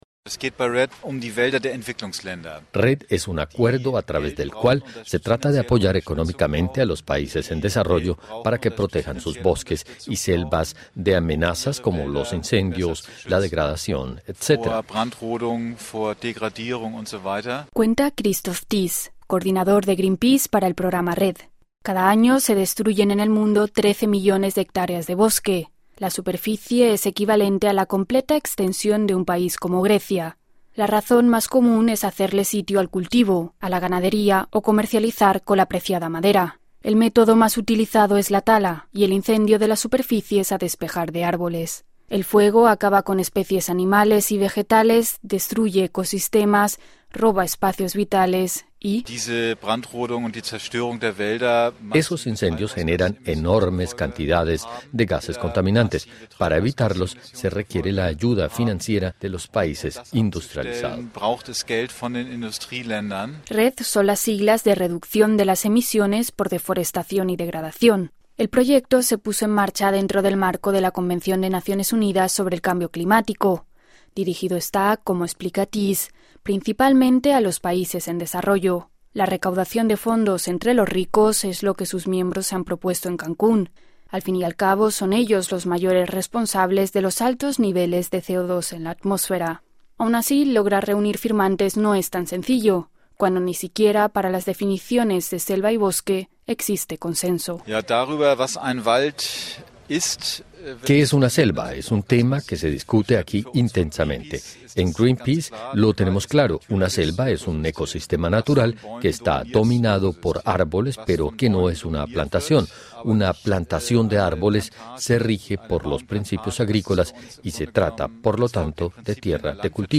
En la cumbre climática, se intenta aprobar un acuerdo para apoyar a países en desarrollo en la protección de bosques. Escuche el informe de Deutsche Welle.